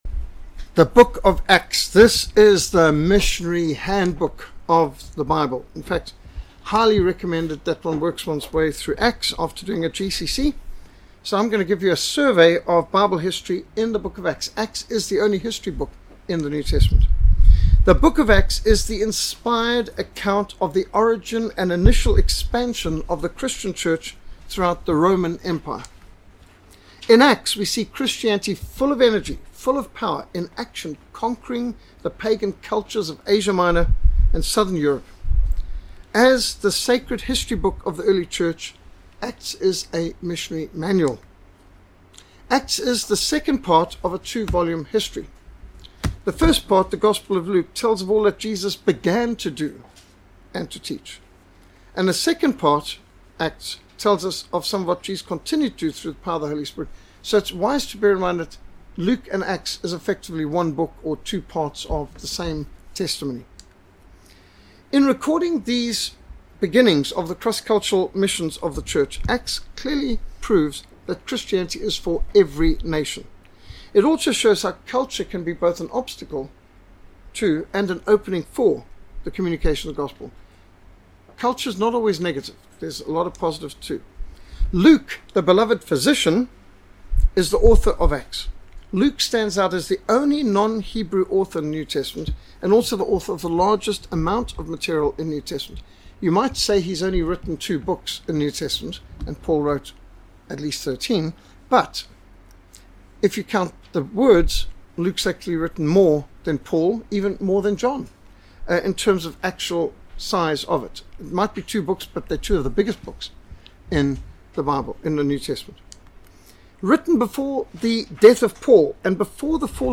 Bible Survey - Acts | SermonAudio Broadcaster is Live View the Live Stream Share this sermon Disabled by adblocker Copy URL Copied!